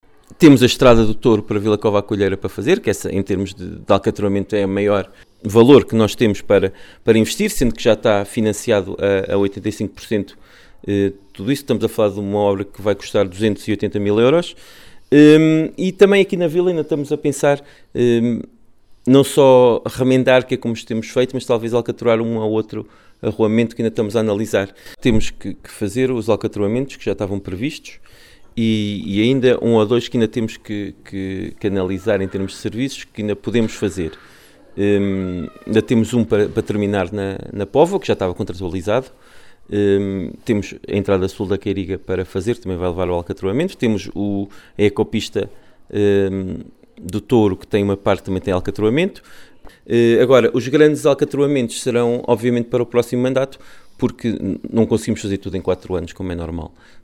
Paulo Marques, Presidente do Município, em declarações à Alive FM, fala destas requalificações.